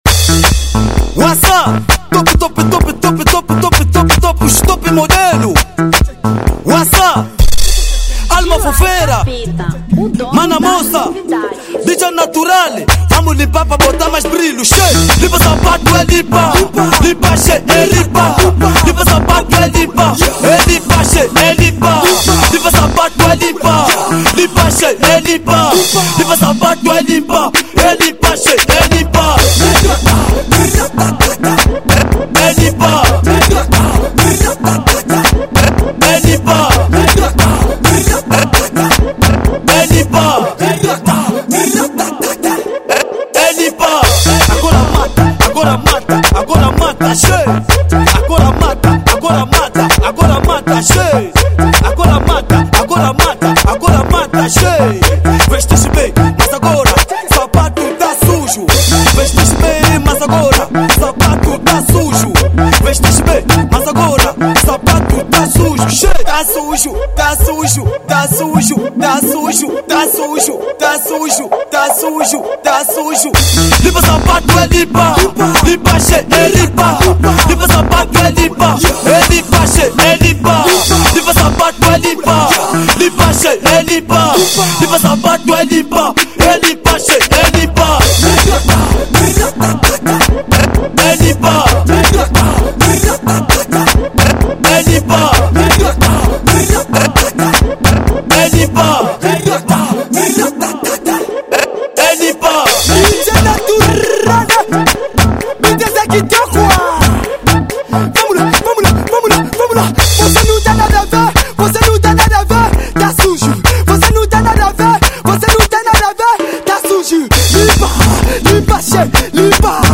Afro House 2021